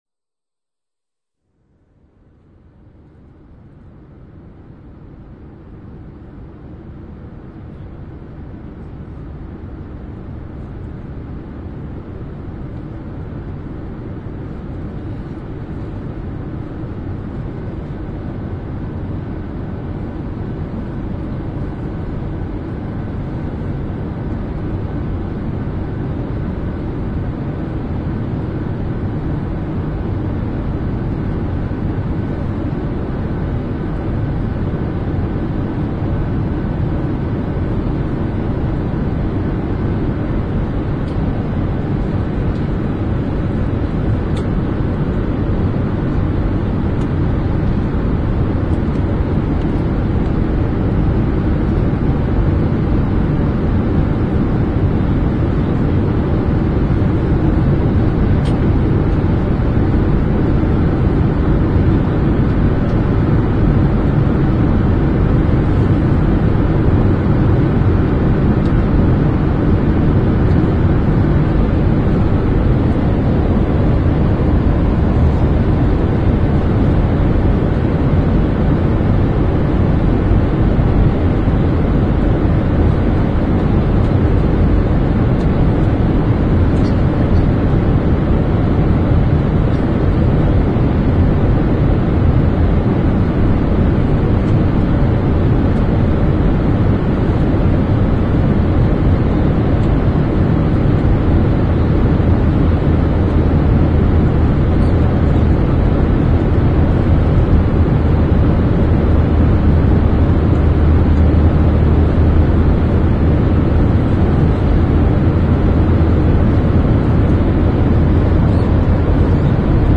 Field Recordings from Spain, Gibraltar and Morocco
1. Turbines – Noises of the air
The „Estrecho De Gibraltar“-recordings are all left in their original form.
Recorded in Spain, Gibraltar, Morocco / 2006